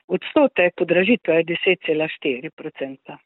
izjava zupanja Lesjak 2 ZA SPLET.mp3